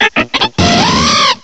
sovereignx/sound/direct_sound_samples/cries/heliolisk.aif at master